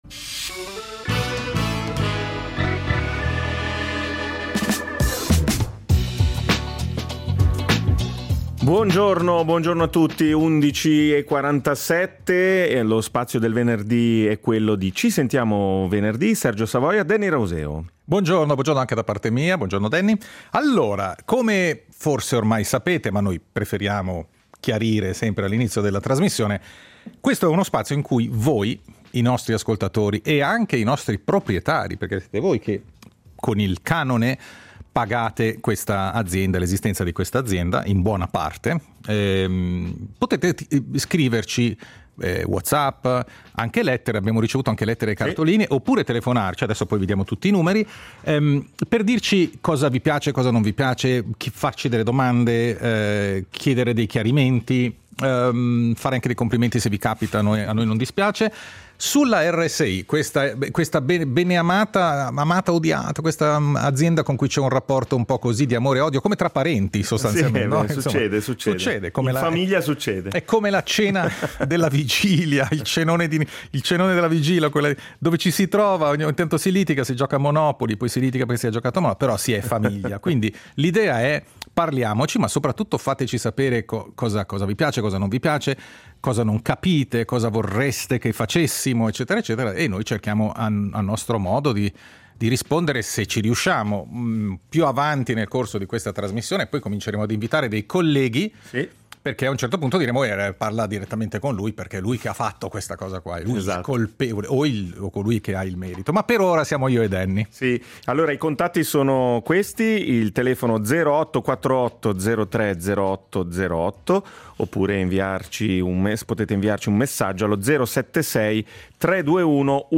Insomma, “Ci sentiamo venerdì” è il tavolo radiofonico dove ci si parla e ci si ascolta.